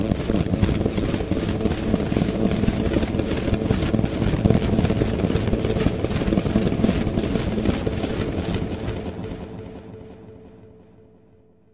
描述：VSTi Elektrostudio ODSay +镶边+均衡器+多效果
Tag: 的VSTi ODSay 效果 Elektrostudio FX SFX